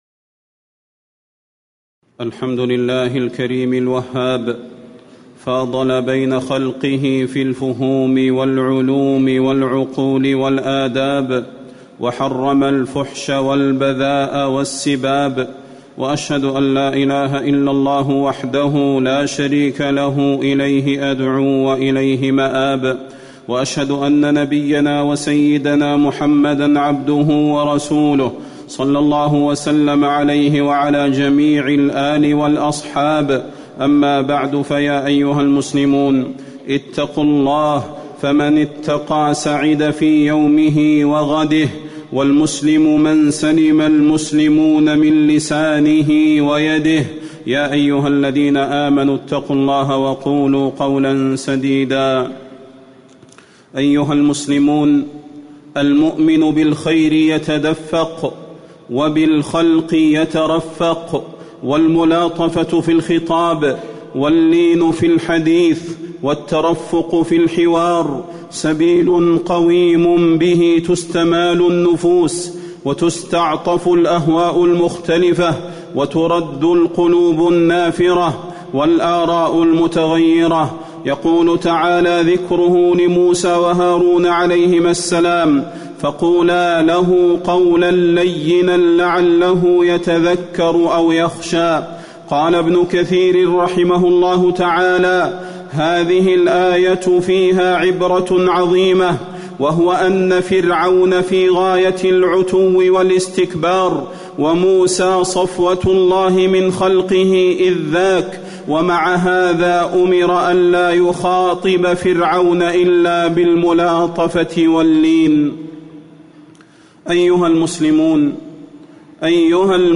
تاريخ النشر ٢٤ شوال ١٤٣٧ هـ المكان: المسجد النبوي الشيخ: فضيلة الشيخ د. صلاح بن محمد البدير فضيلة الشيخ د. صلاح بن محمد البدير وجوب حسن الخلق